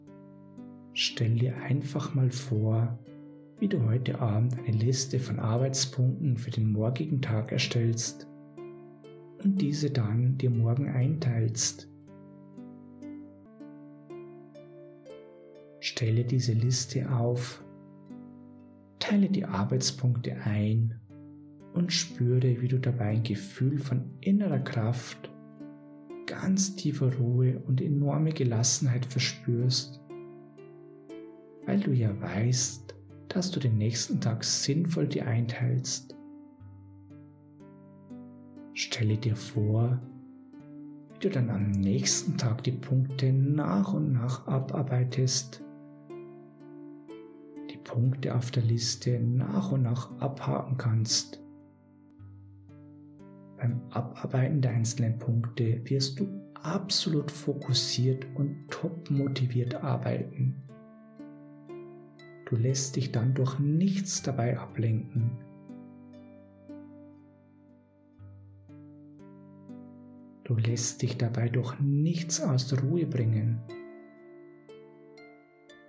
Mit dieser geführten Hypnose-MP3 erhalten Sie die Möglichkeit, Ihr Zeitmanagement in den Griff zu bekommen und zu verbessern.
Die Sitzung beginnt mit einer herrlich entspannenden Einleitung in die Hypnose, um Ihren Körper und Geist zu beruhigen und einmal ganz tief zu entspannen.